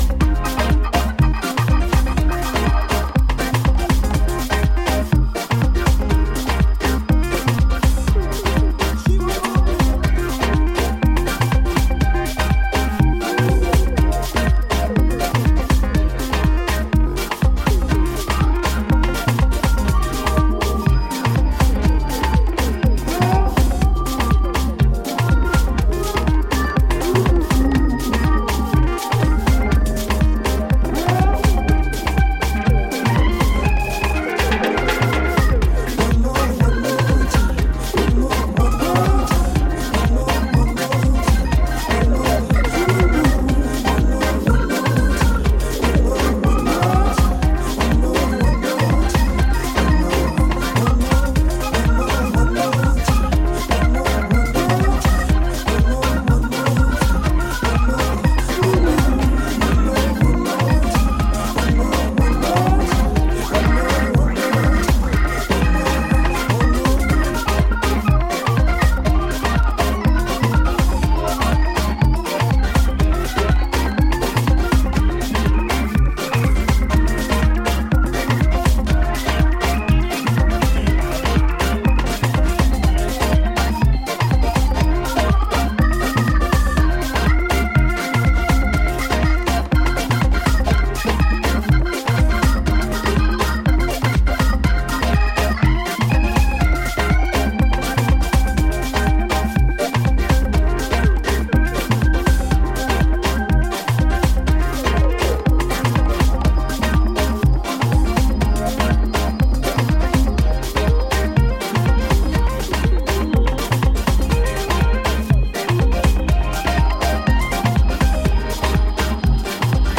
with an electronic pan-African twist